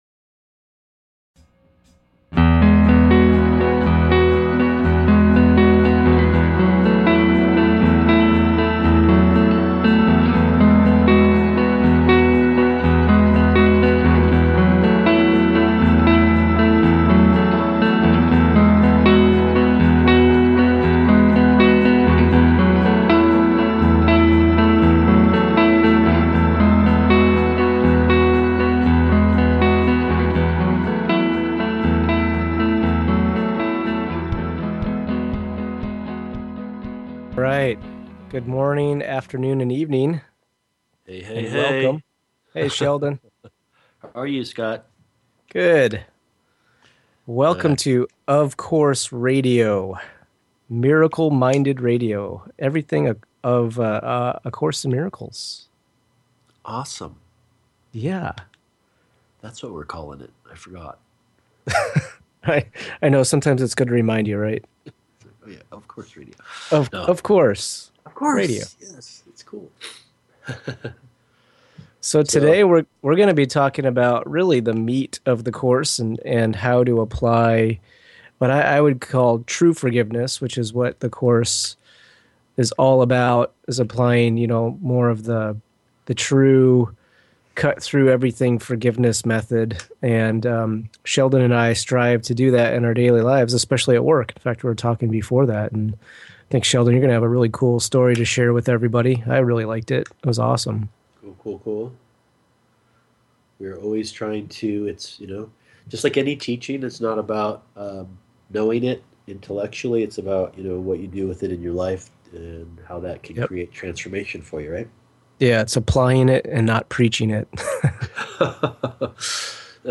Of Course Radio is a lively broadcast focused on the messages within ‘A Course in Miracles’ as well as in-depth explorations into how we live forgiveness in our daily lives and remember our connection with God/Source.
Talk Show